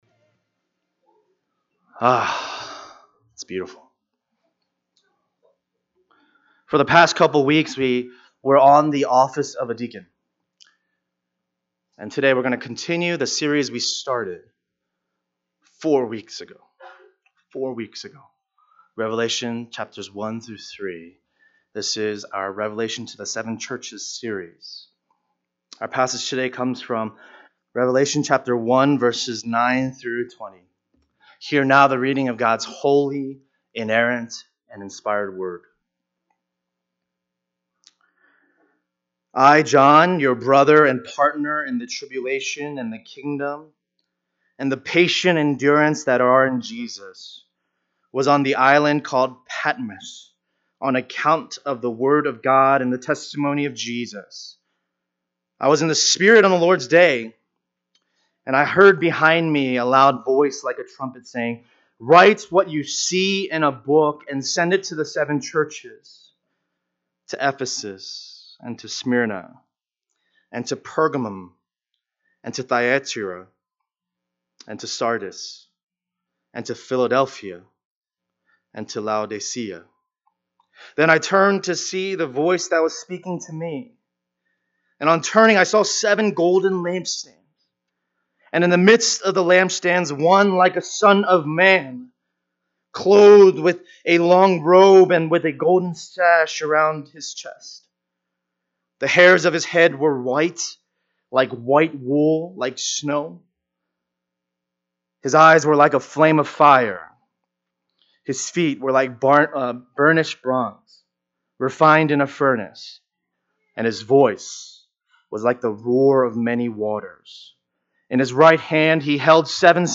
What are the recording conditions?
To the Seven Churches Passage: Revelation 1:9-20 Service Type: Sunday Afternoon « The Necessity for Deacons Revelation